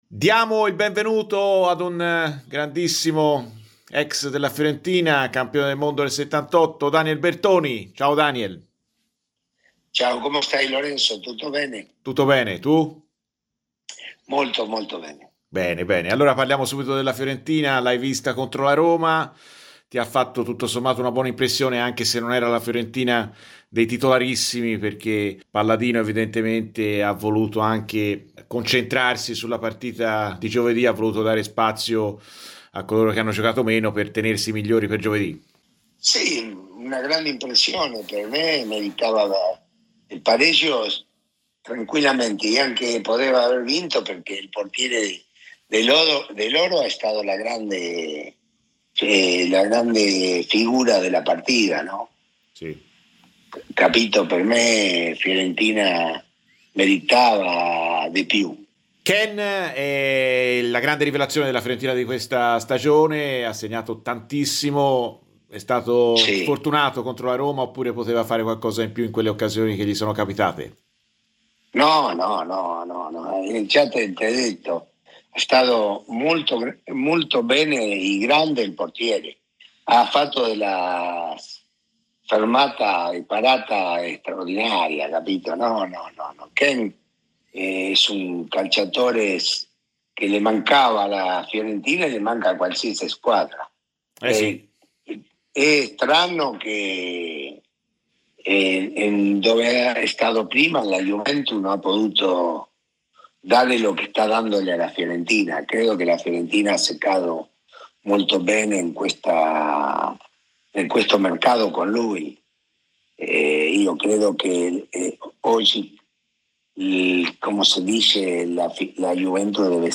Daniel Bertoni, ex Fiorentina, ha parlato a Radio FirenzeViola nel corso di "Viola amore mio" per commentare il momento attuale della squadra di Palladino: "La Fiorentina mi ha fatto una grande impressione a Roma, per me meritava un pareggio o addirittura una vittoria. Il loro portiere è stato un grande fattore della partita".